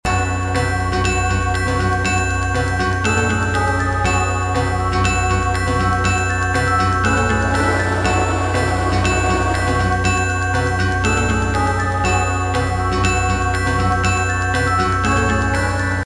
試聴用 MP3ファイル ループ再生になっておりますので、BGMなどの参考にしてください。
POINT 不協和音を入れて怪しげな感じにしました。
BGM 暗い 普通